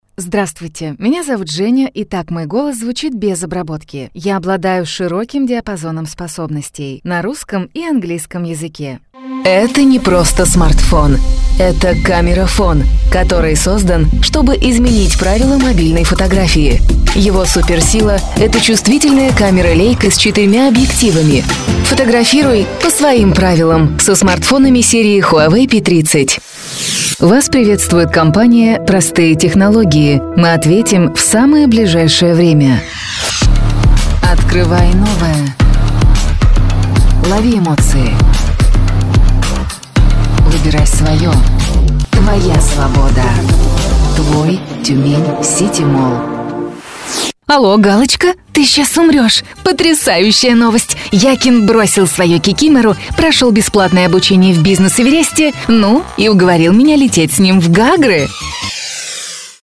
Тракт: Конденсаторный микрофон SE ELECTRONICS SE X1 VOCAL PACK, USB-АУДИО ИНТЕРФЕЙС ROLAND QUAD-CAPTURE